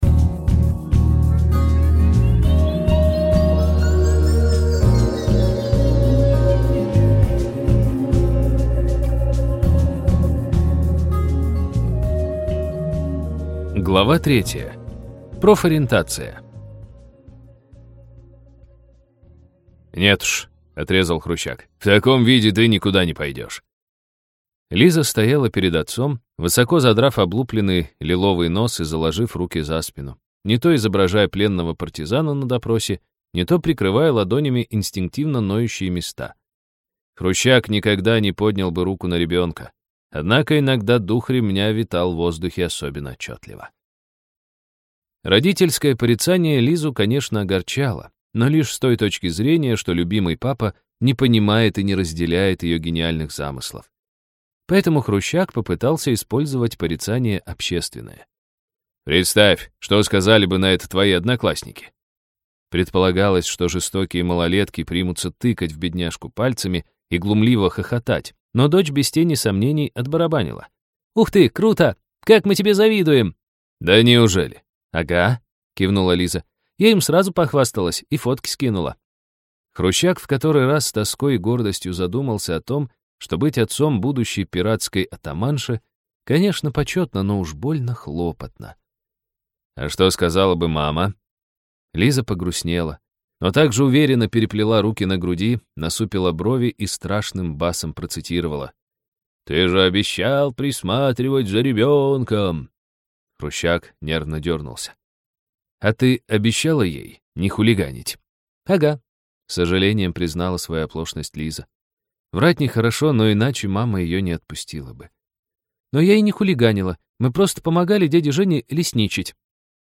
Аудиокнига Киберканикулы. Часть 3.